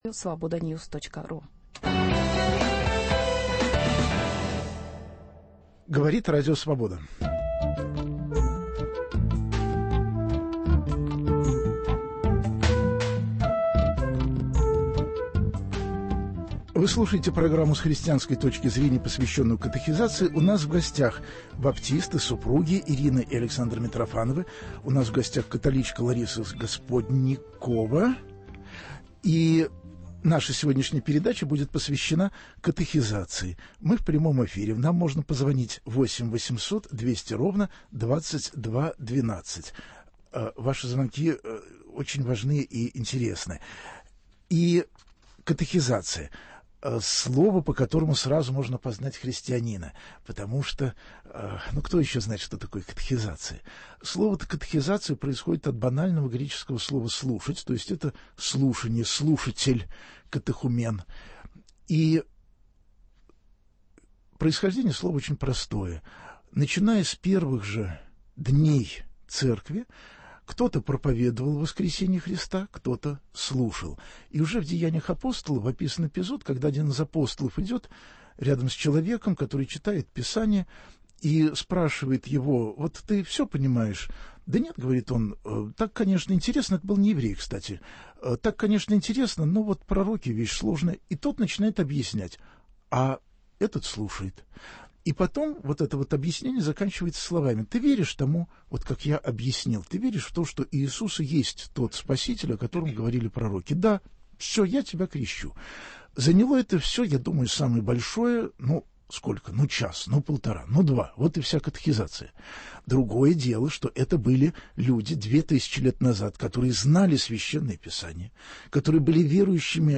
Но если в течение веков Церковь обходилась без катехизации, нужна ли она сегодня? Зачем нужна, всем нужна или только детям, какой она должна быть? Об этом будут вести разговор представители и католичества, и протестантизма, и - последние в перечне, но не по грехам - православные.